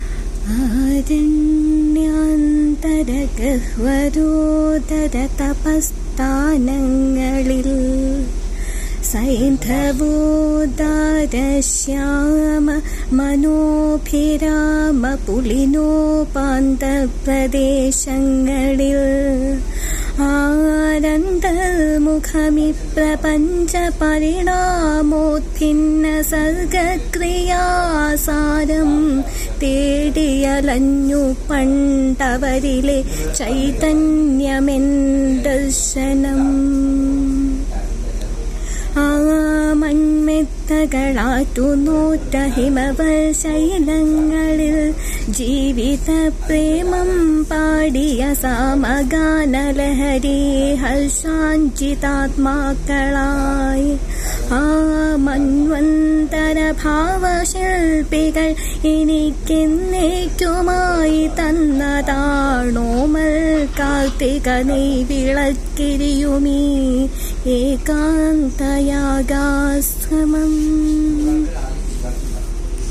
ఆమె తుంచెన్ పండగలో పాల్గోడానికి వచ్చింది.
ఆయన కవిత ఏదైనా వినిపిస్తావా అని అడిగితే వెంటనే ఒక పద్యం వినిపించింది.
ఒక నవయువతి తన భాషలో ఒక కవి పద్యం వినిపించడం- ఇలా తెలుగులో ఎవరు వినిపించినా నేను జీవితకాలం వారికి అభిమానిగా మారిపోగలను. ఆమె వినిపించిన పద్యం వినండి.